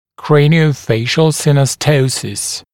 [ˌkreɪnɪə(u)ˈfeɪʃl ˌsɪnɔˈstəusəs][ˌкрэйнио(у)ˈфэйшл ˌсиноˈстоусэс]черепно-лицевой синостоз